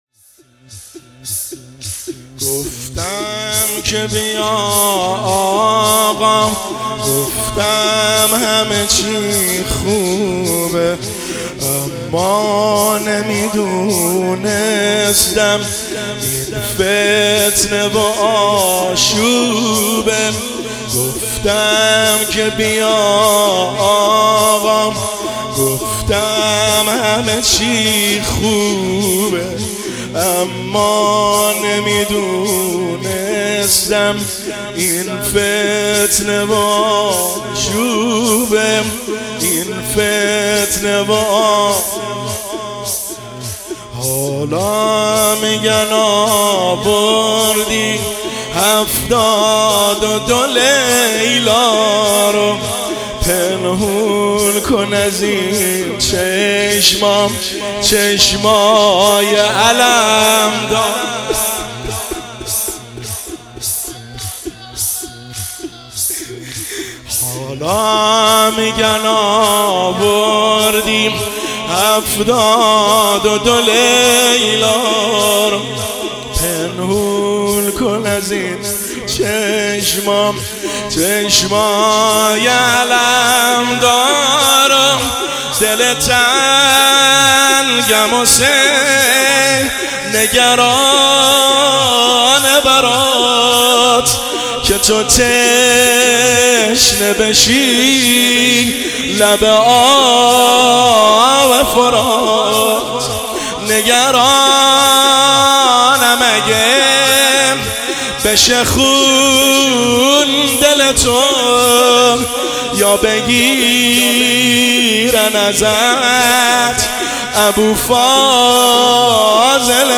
زمینه – شب اول محرم الحرام 1404